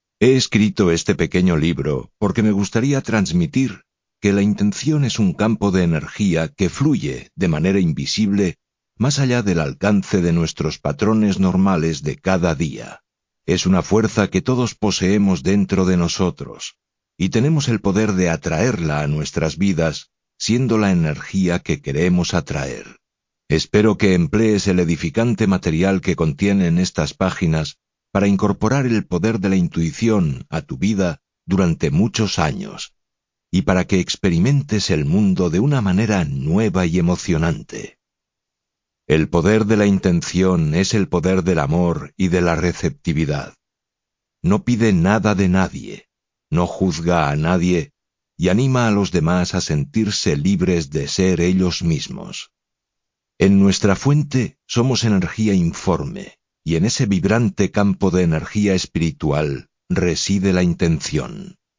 audiolibro La fuerza invisible 365 maneras de aplicar el poder de la intencion de tu vida Wayne W Dyer